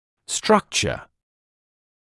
[‘strʌkʧə][‘стракчэ]структура, конструкция